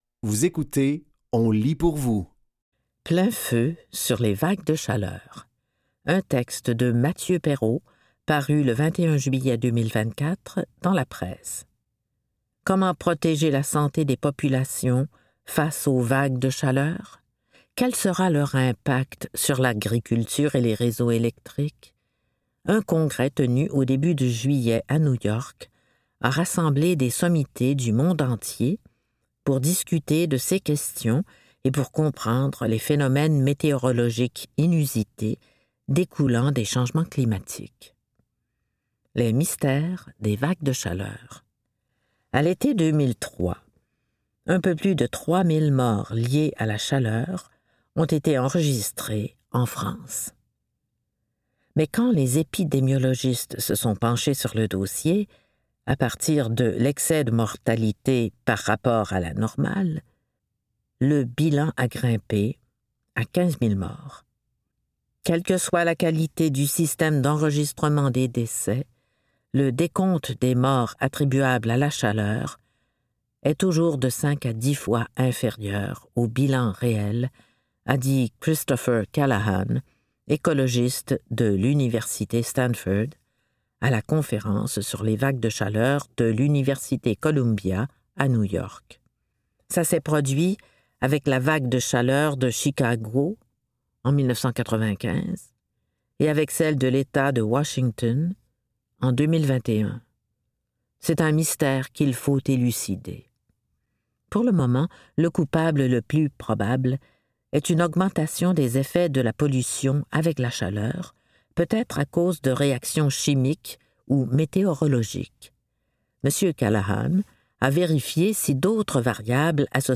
Dans cet épisode de On lit pour vous, nous vous offrons une sélection de textes tirés des médias suivants : La Presse, Fugues, et Le Devoir.